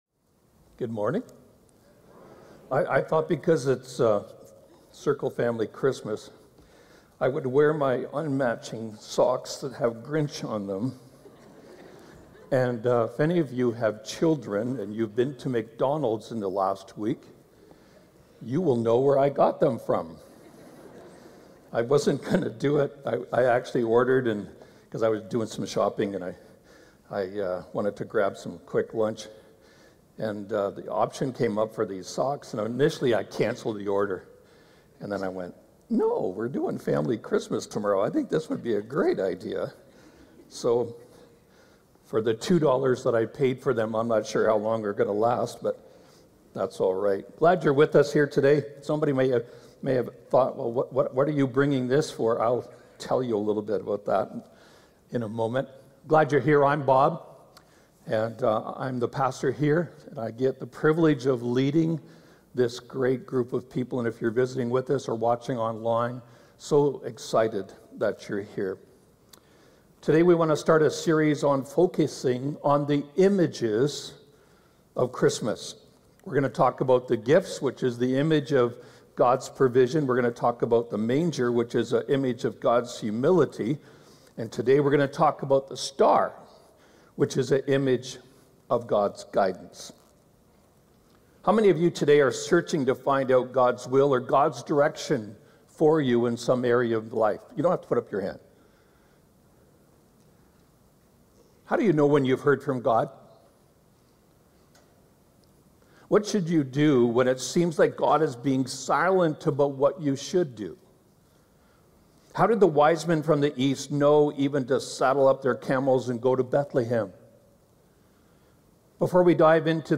Sunday morning talks from Circle Drive Alliance in Saskatoon. Engaging, helpful messages from CircleYXE.